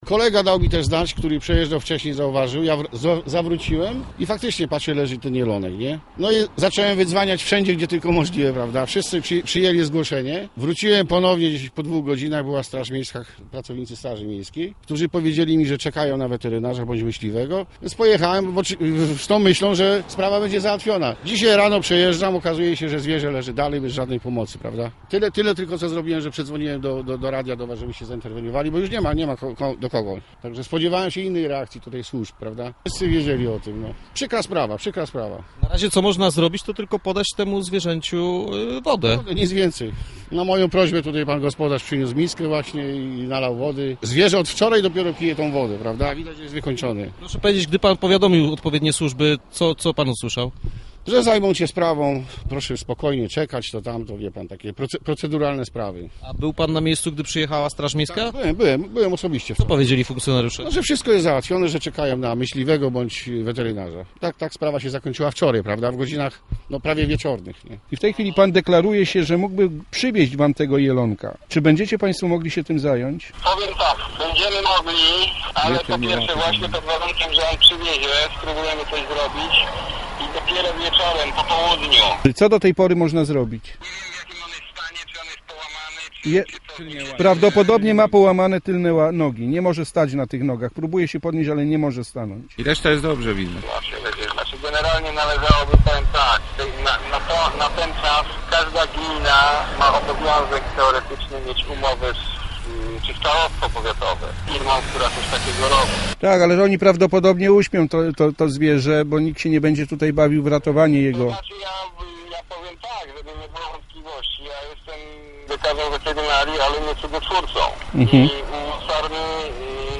Rozmowa ze słuchaczem, który powiadomił nas o całej sprawie; rozmowa z lekarzem weterynarii, który zgodził się przyjąć ranne zwierzę.